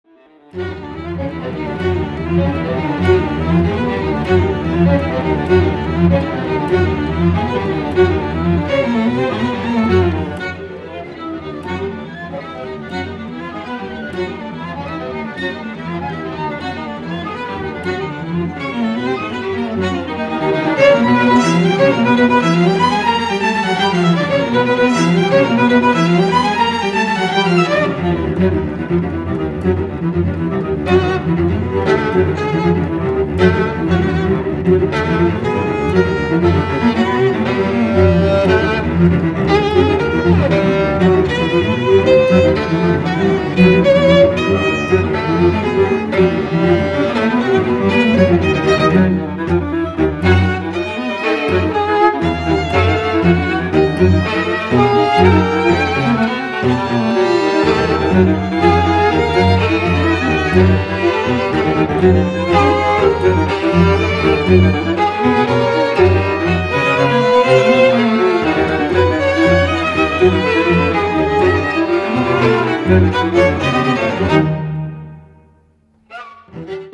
Jazz Music and More